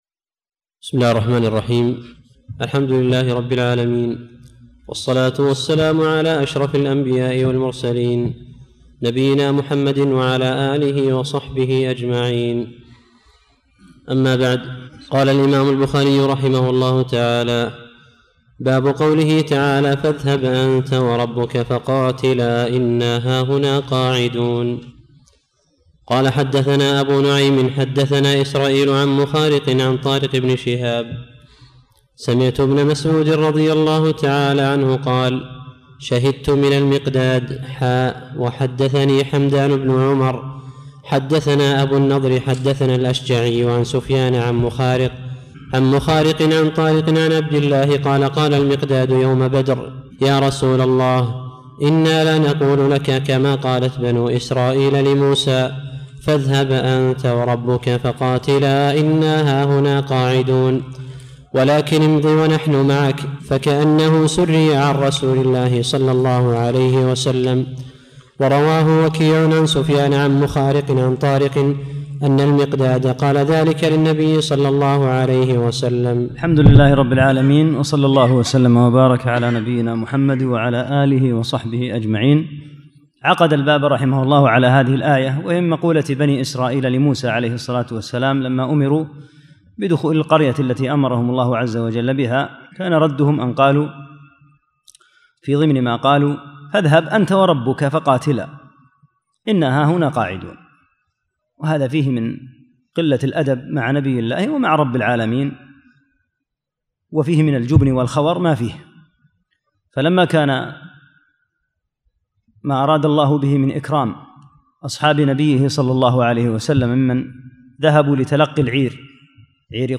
10 - الدرس العاشر